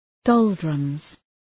Shkrimi fonetik {‘dəʋldrəmz}
doldrums.mp3